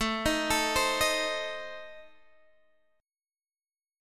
A Diminished